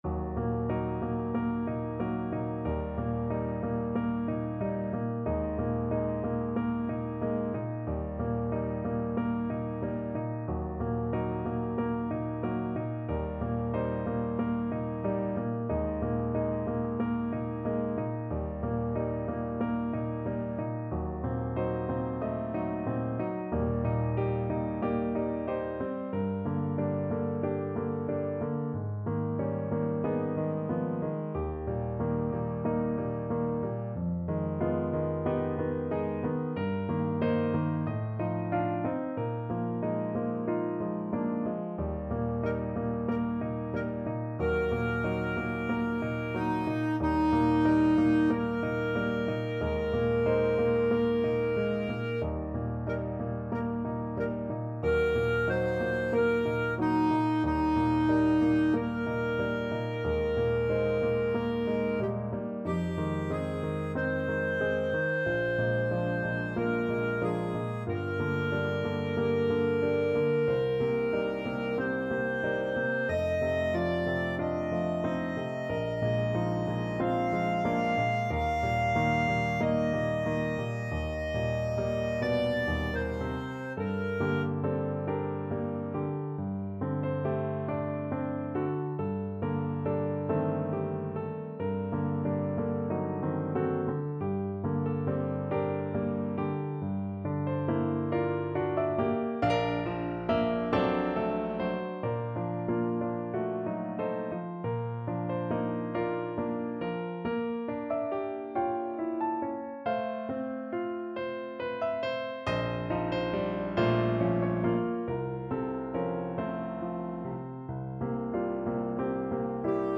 Clarinet version
Andante (=46)
Eb4-Eb7
4/4 (View more 4/4 Music)
Classical (View more Classical Clarinet Music)